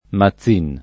[ ma ZZ& n ]